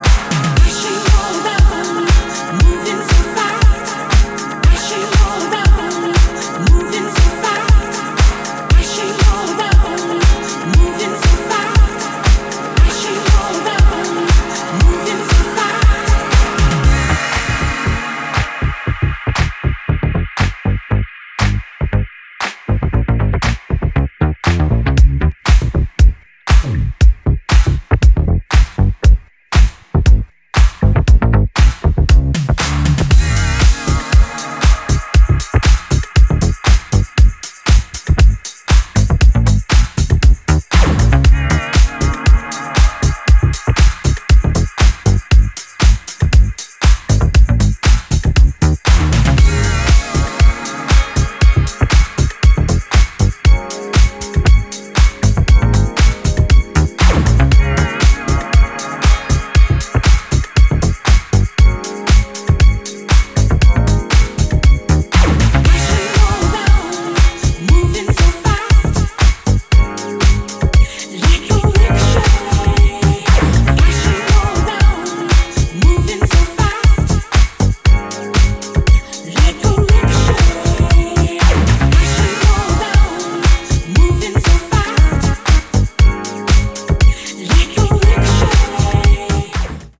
uplifting and energetic brilliance